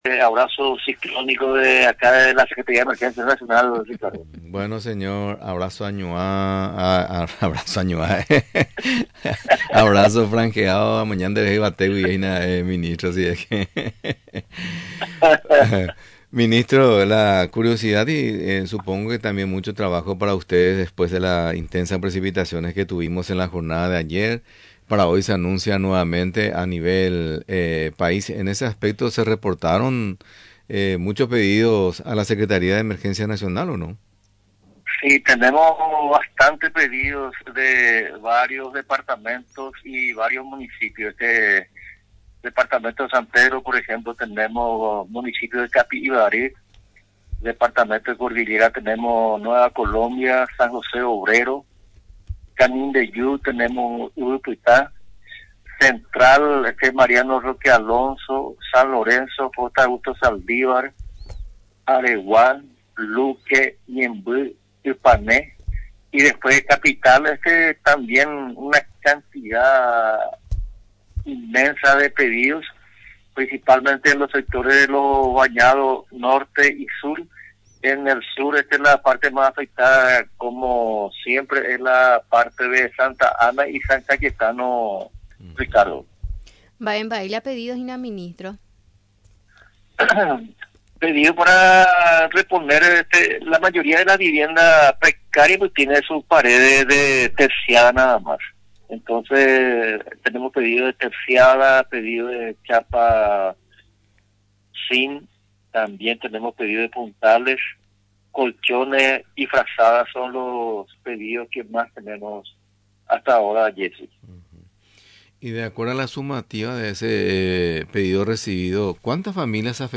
Durante la entrevista en Radio Nacional del Paraguay, mencionó el impacto que causó en los citados distritos del departamento Central, la temporal que se desató en las últimas horas.